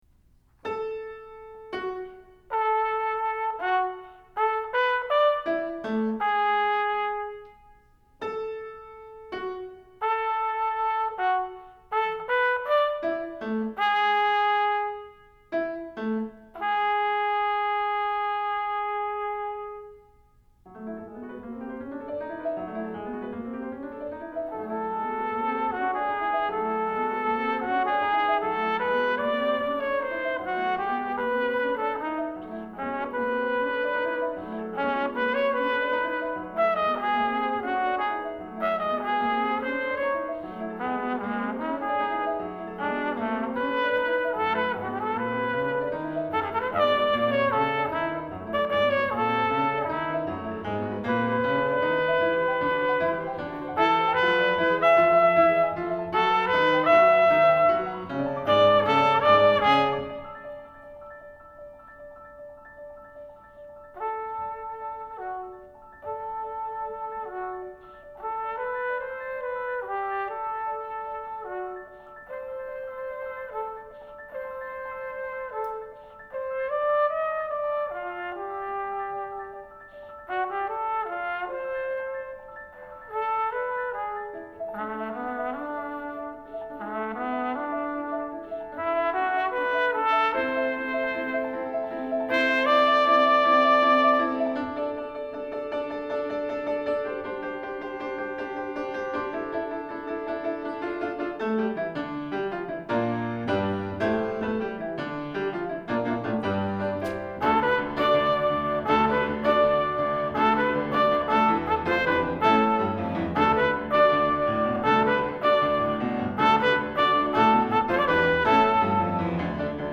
for flugelhorn and piano is a brief, fun work